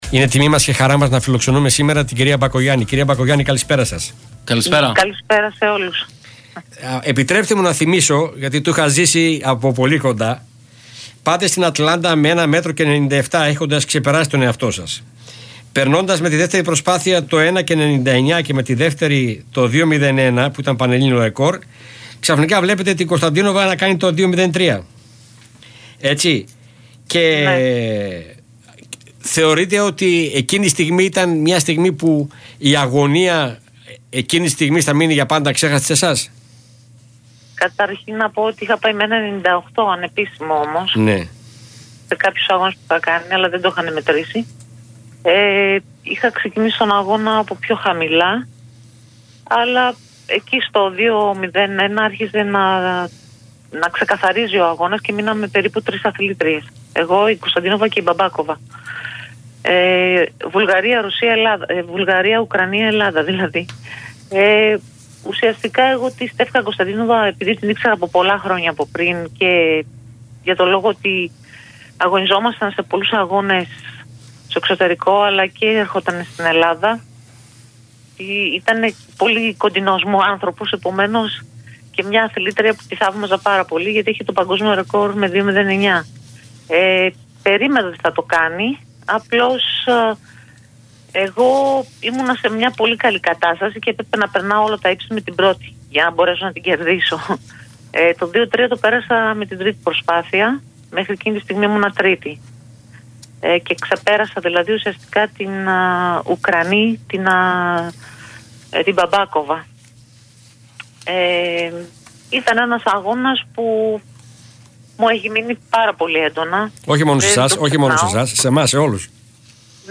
Νίκη Μπακογιάννη στον Real fm 97,8: Οι συνθήκες λόγω του κορονοϊού είναι δύσκολες και οι αθλητές πρέπει να κάνουν υπομονή